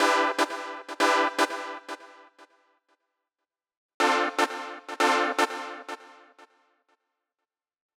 29 Synth PT1.wav